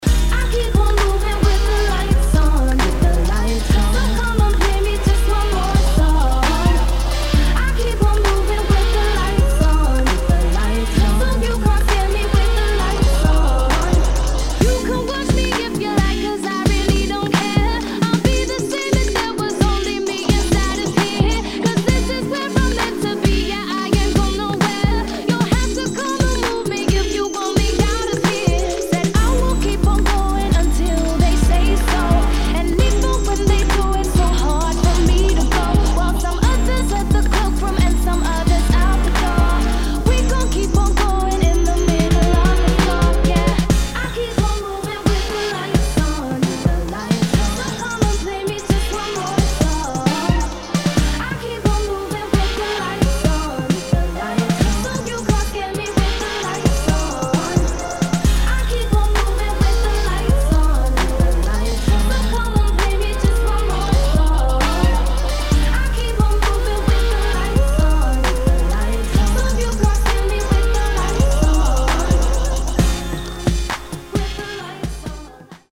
[ DUBSTEP / UK GARAGE ]